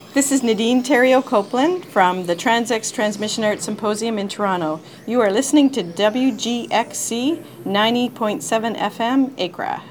Transmission Arts & Experimental Sounds
Recorded at the Trans X Transmission Arts Symposium in Toronto.